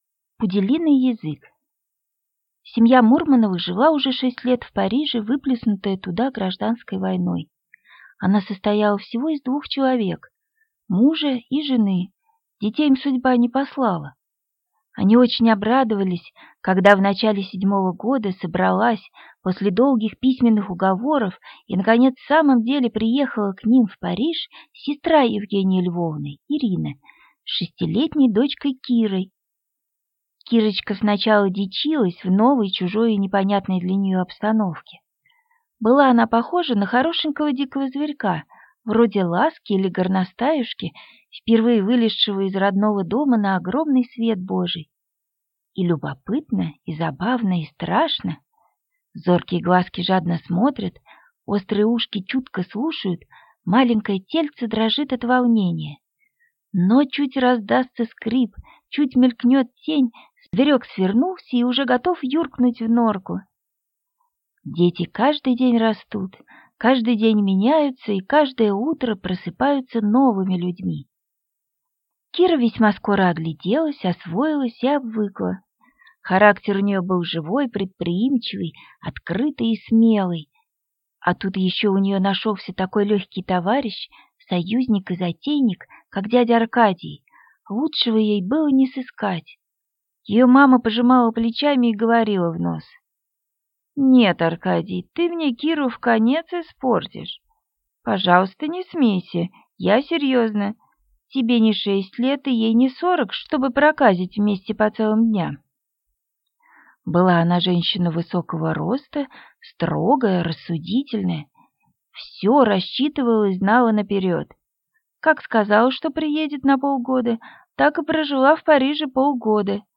Аудиокнига Пуделиный язык | Библиотека аудиокниг